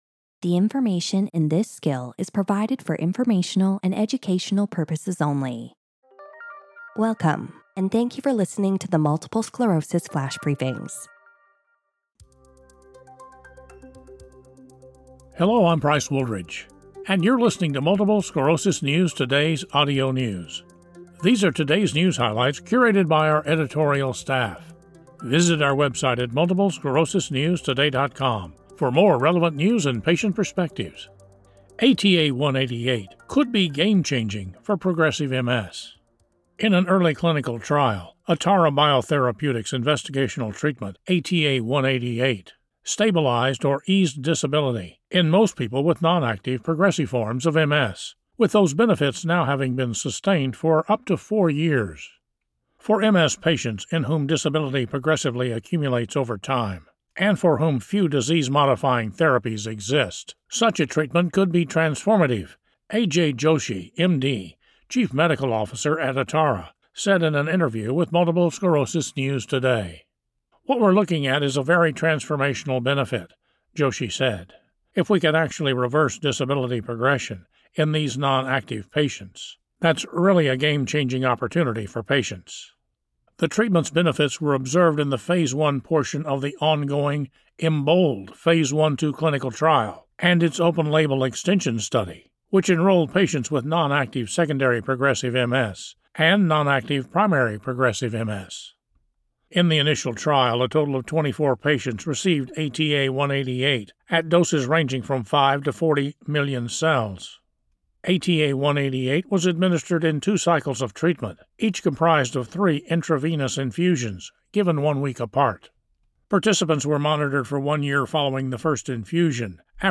reads a news report on ATA188, a treatment targeting the Epstein-Barr virus which could be integral in treating progressive MS.